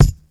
• 2000s Lo-Fi Kick Single Hit F Key 14.wav
Royality free kick drum sample tuned to the F note. Loudest frequency: 521Hz